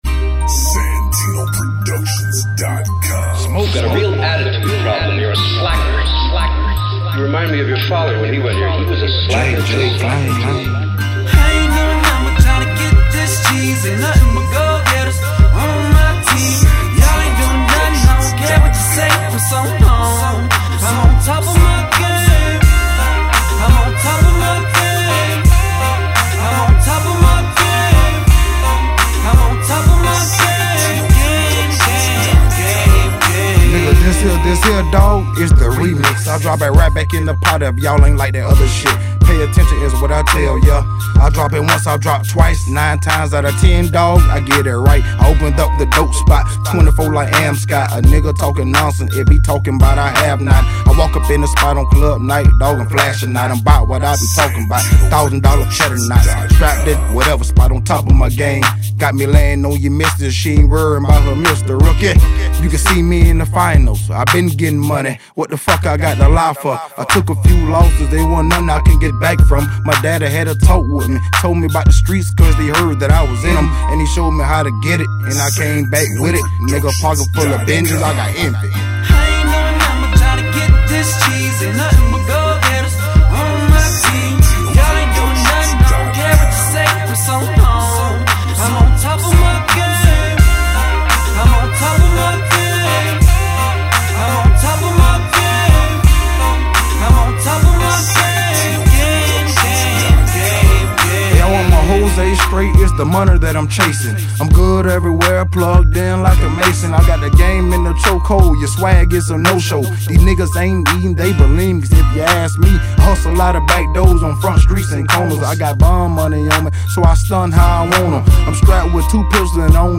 Hiphop
Description : Real Hot Street Music